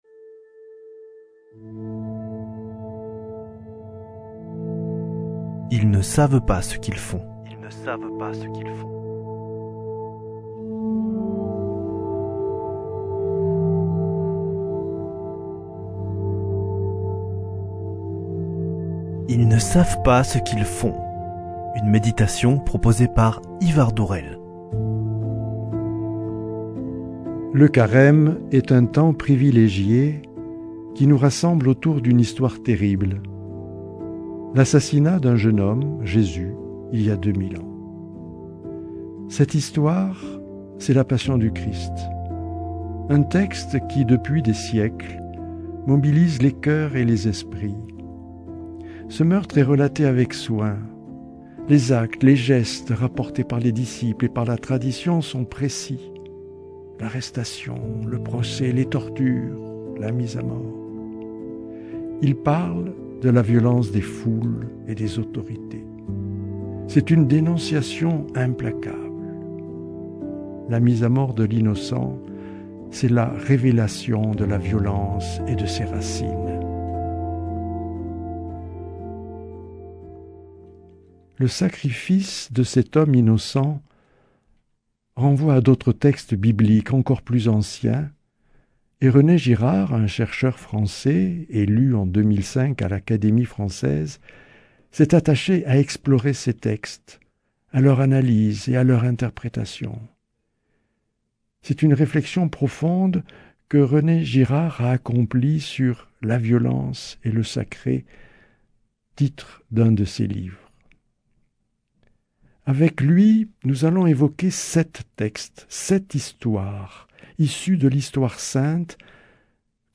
Des extraits musicaux de la Passion selon Saint Mathieu (Jean Sébastien Bach) accompagnent cette méditation.